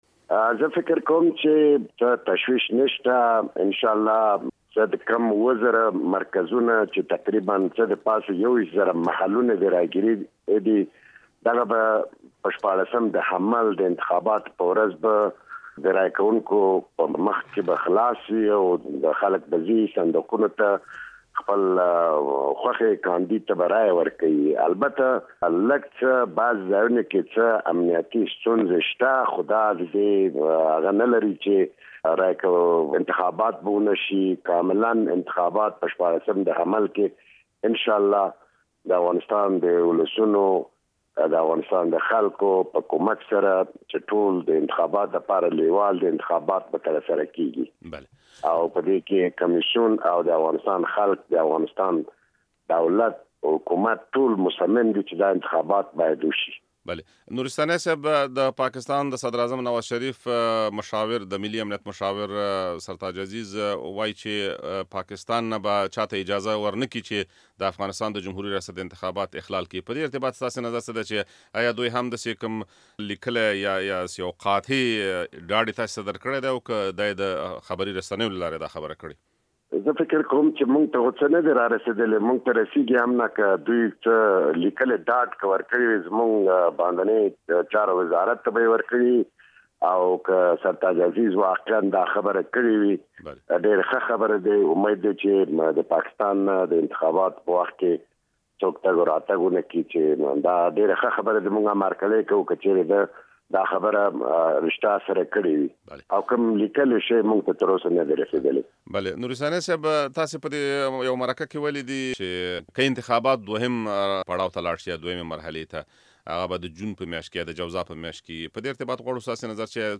له يوسف نورستاني سره مرکه